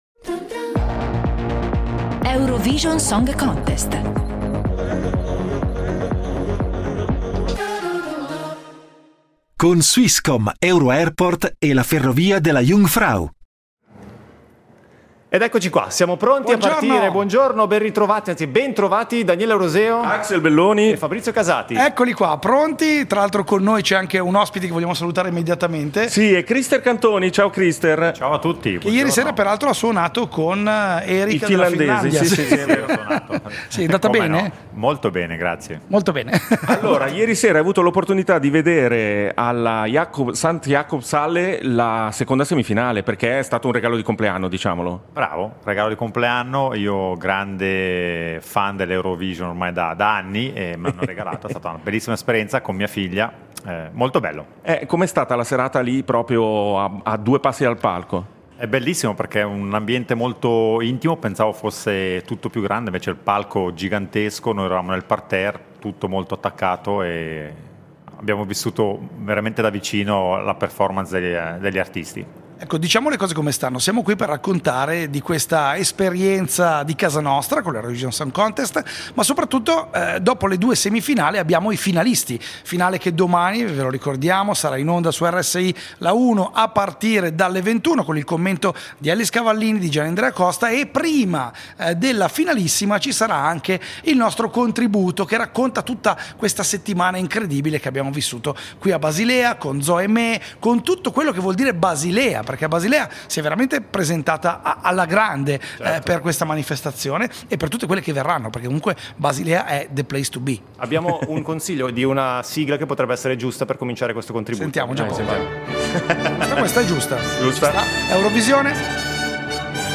In diretta da Basilea